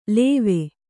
♪ lēve